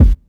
31 kick hit.wav